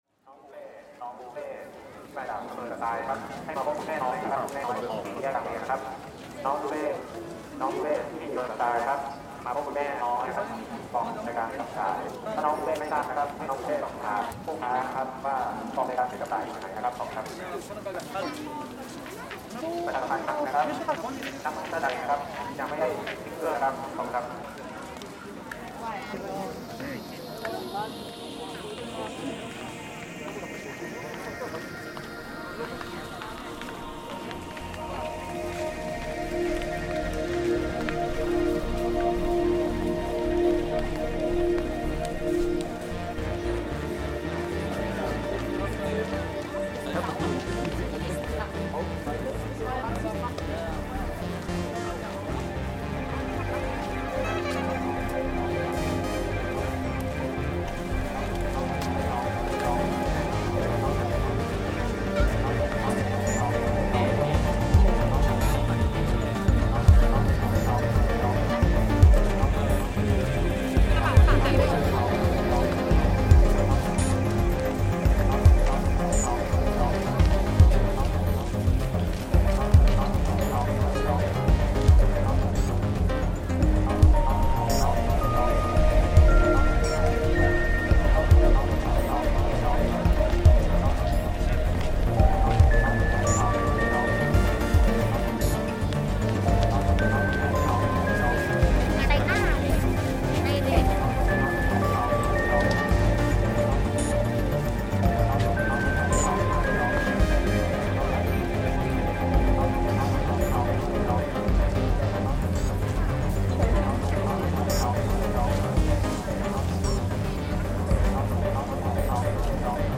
Chiang Mai night market reimagined